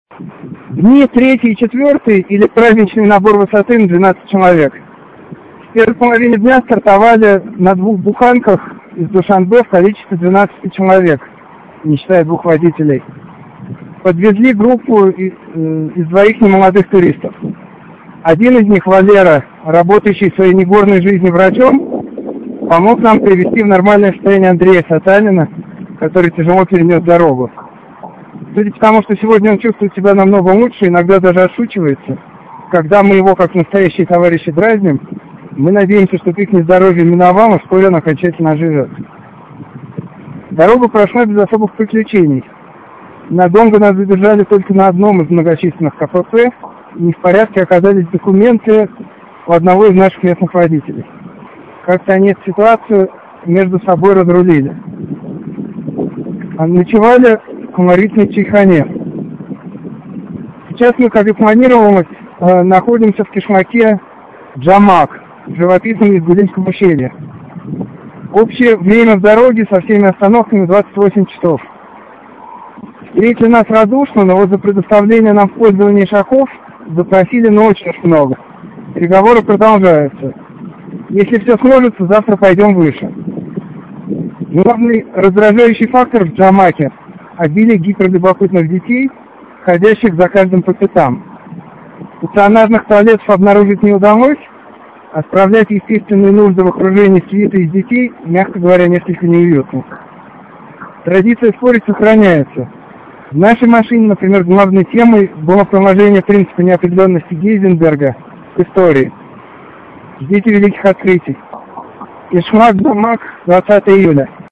Аудио сообщение.